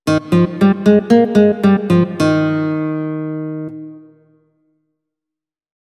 -Selectie Super Art. Concert Guitar
Wanneer er staccato wordt gespeeld, is het probleem er niet. Staccato houdt in dat je een volgende noot speelt, nadat de voorafgaande noot eerst los is gelaten.
Staccato.mp3